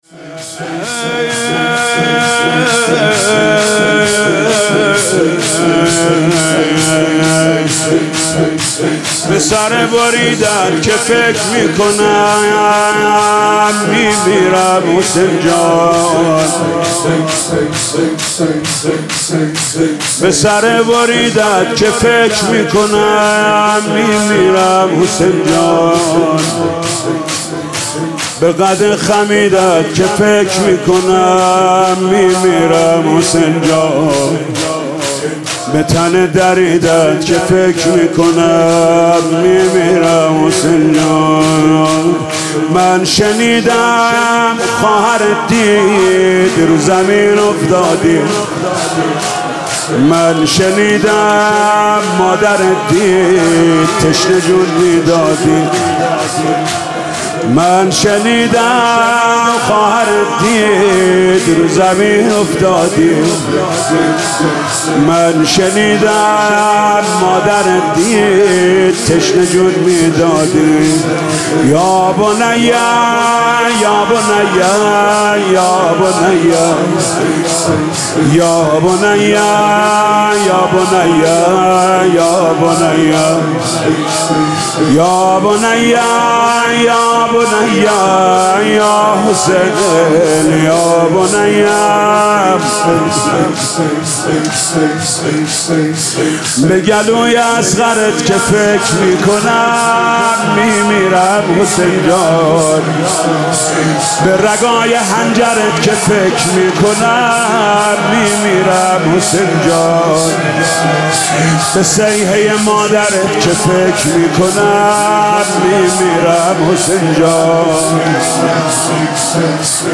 برگزاری مراسم محرم حسینی 1401